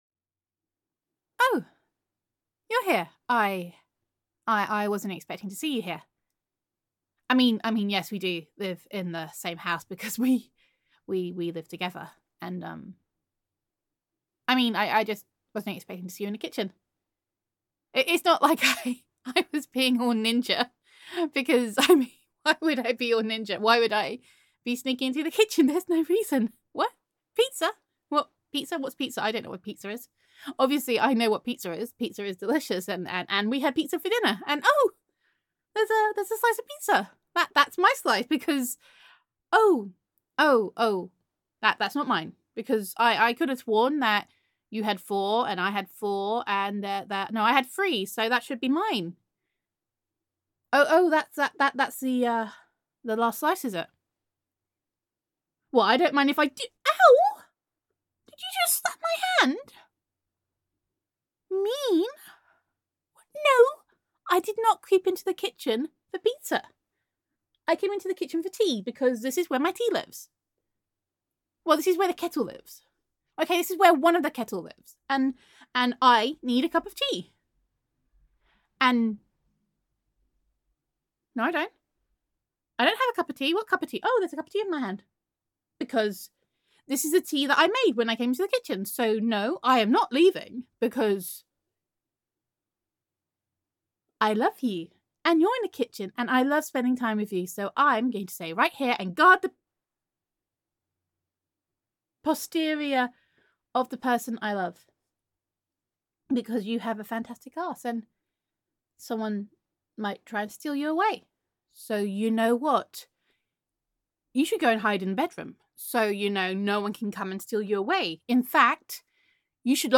[F4A] The Last Slice of Pizza